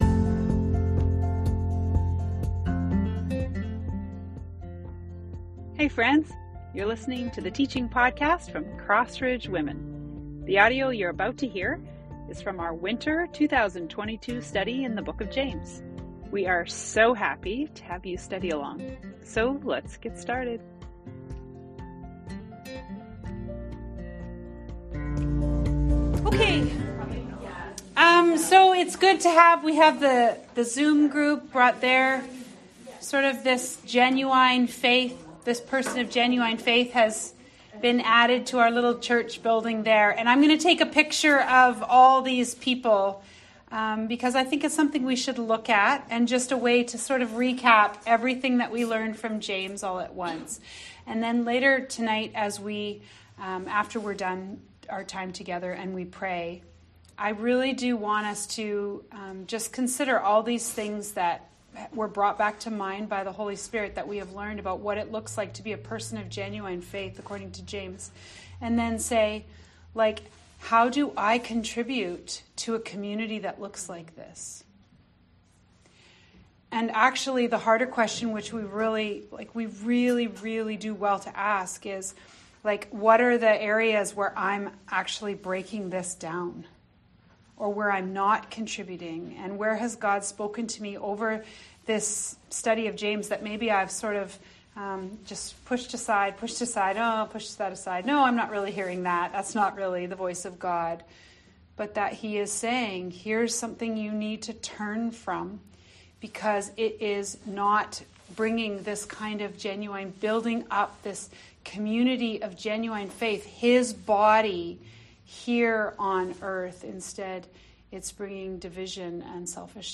Wrap Up teaching from James 5:7-20.